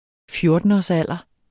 Udtale [ ˈfjoɐ̯dənɒs- ]